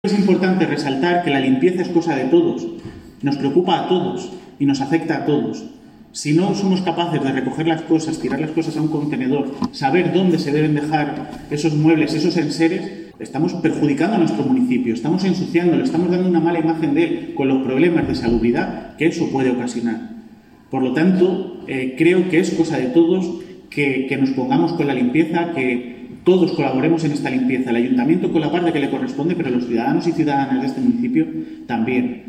Declaraciones del alcalde, Miguel Óscar Aparicio